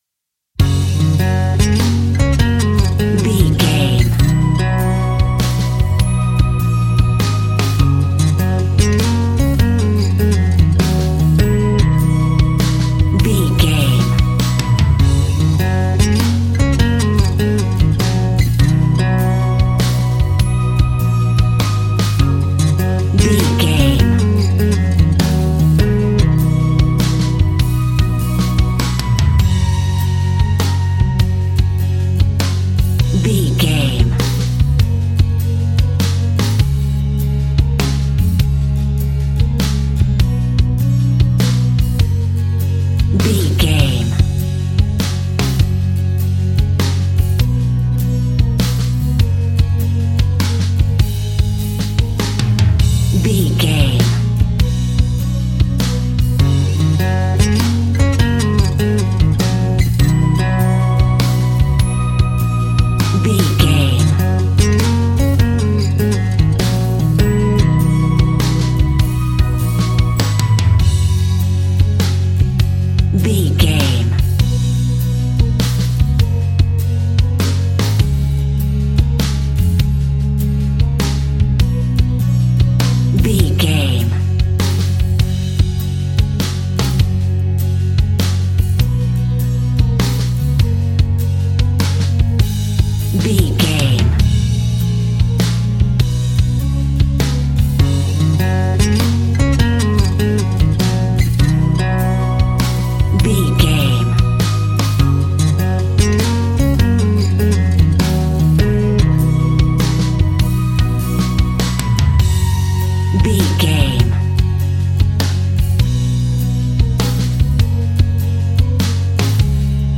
Ionian/Major
indie pop
energetic
uplifting
upbeat
groovy
guitars
bass
drums
piano
organ